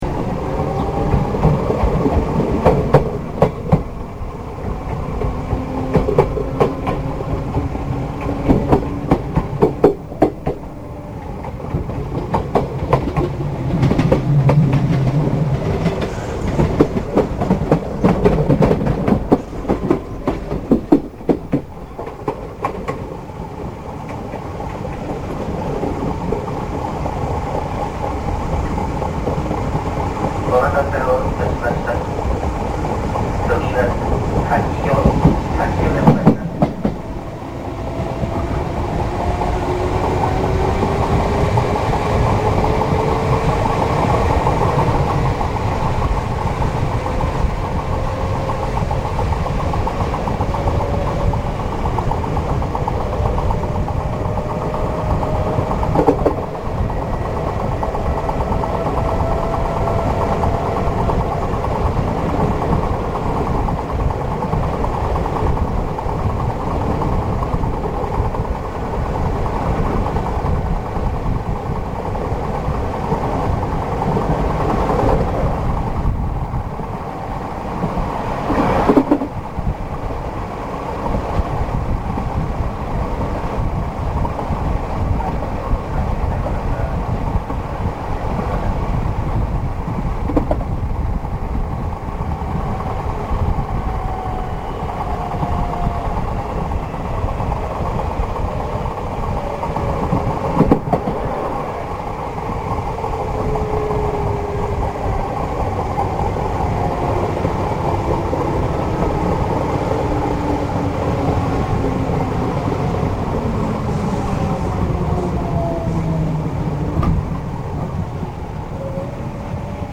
●クハ２４９４号走行音は
新百合ヶ丘−玉川学園前間（ただし、連結面のためウインドノイズもあり、音質はそれほど良くないです）。